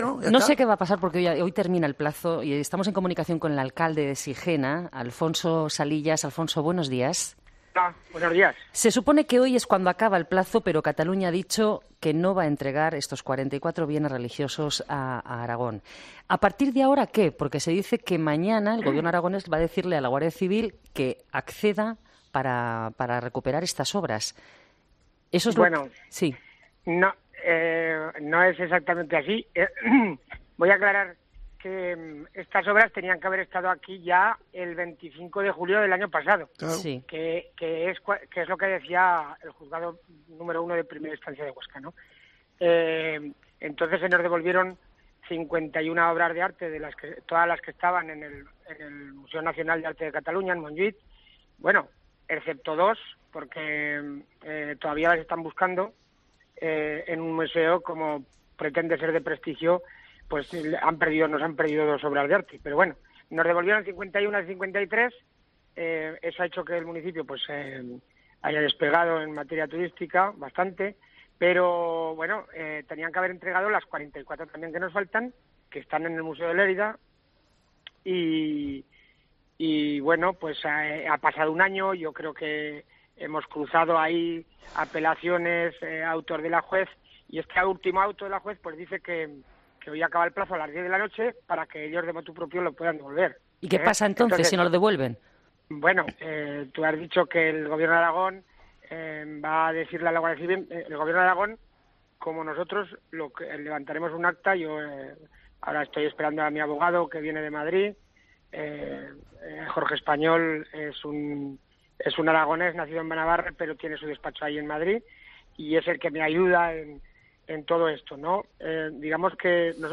Alfonso Salillas, alcalde de Sijena: "Estas obras tenían que estar desde el 25 de julio del año pasado"